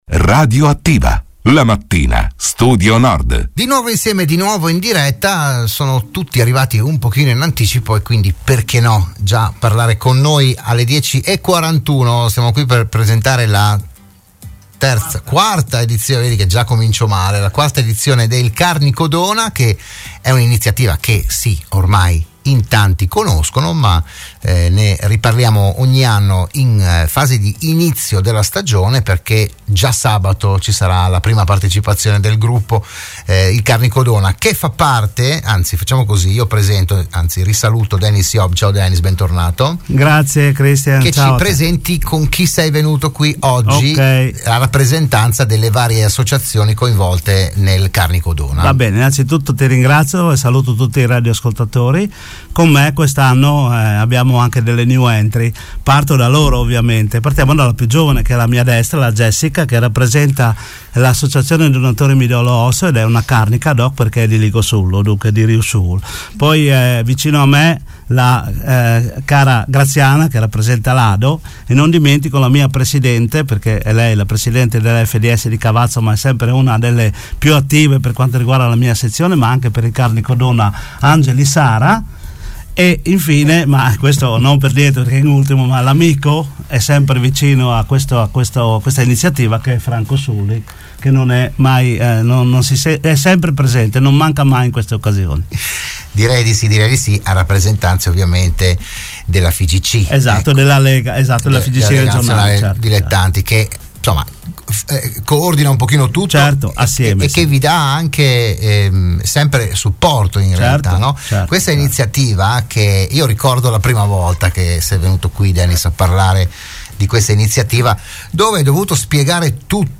Dell'iniziativa si è parlato a "RadioAttiva" di Radio Studio Nord